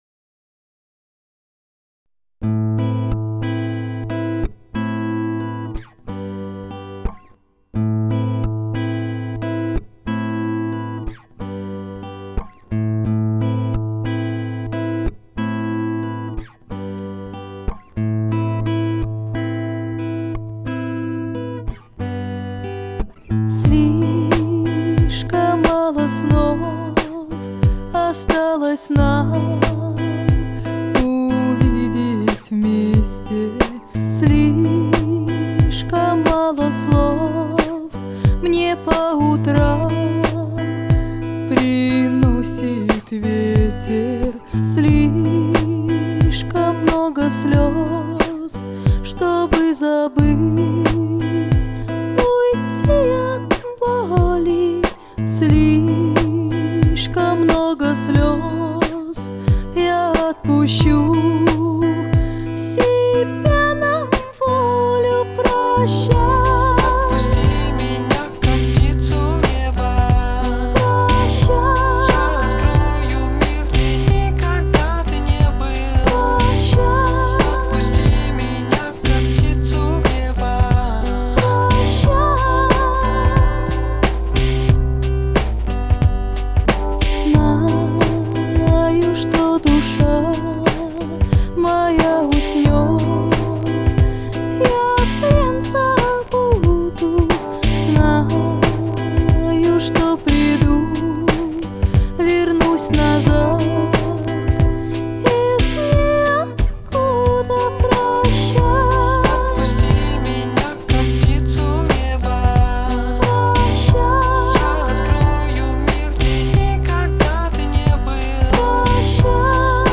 Жанр: ruspop